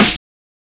SNARE CRACKLE.wav